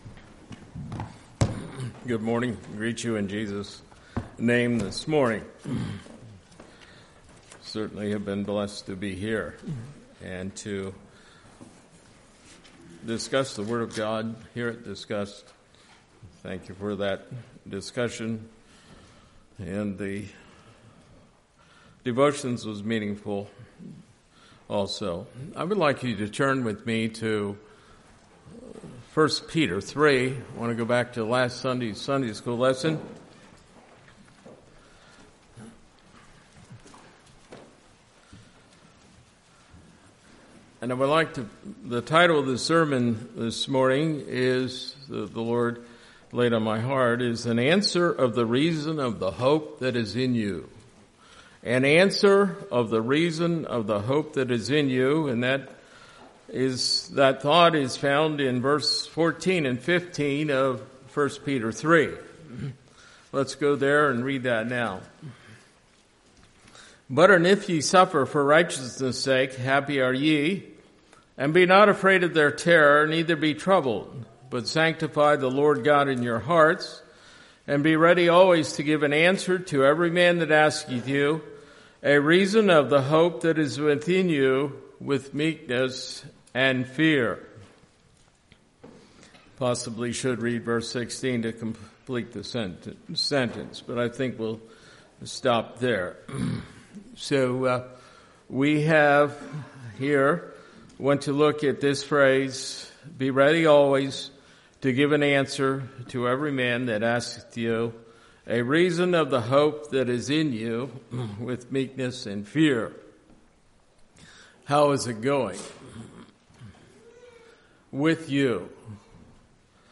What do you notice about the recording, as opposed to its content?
Play Now Download to Device An Answer of the Reason of the Hope That Is in You Congregation: Mount Joy Speaker